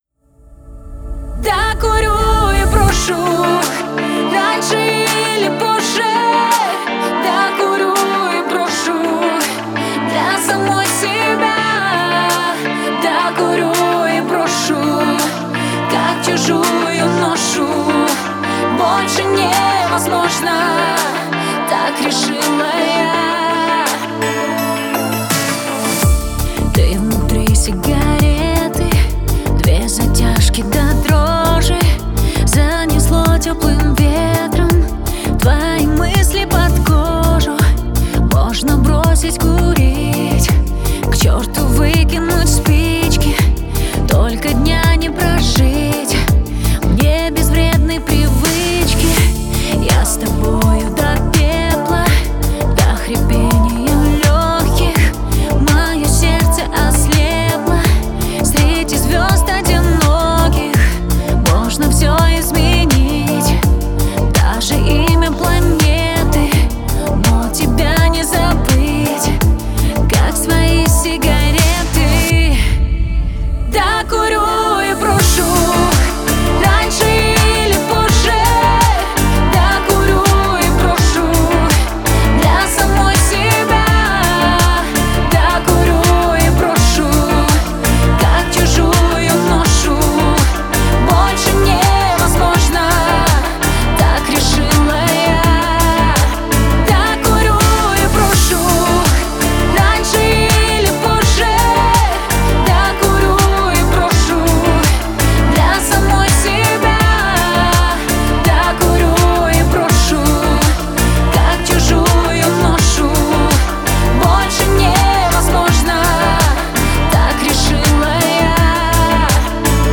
это яркая и запоминающаяся песня в жанре поп